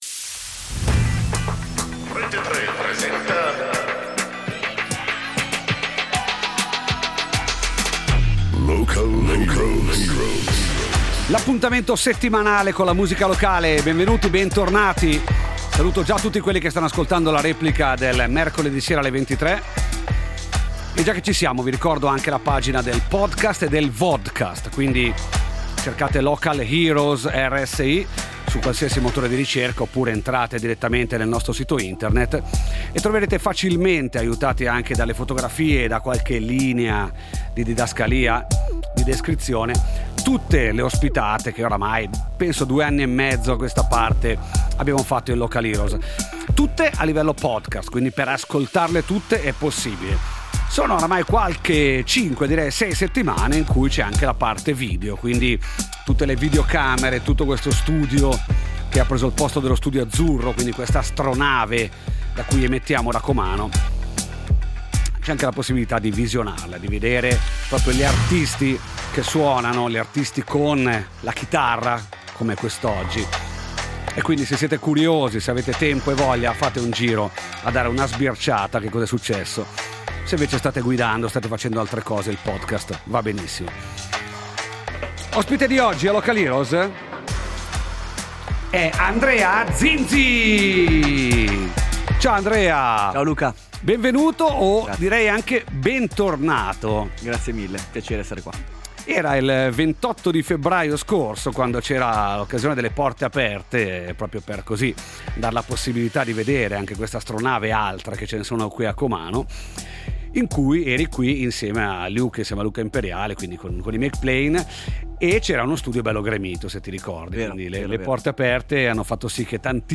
Live Music!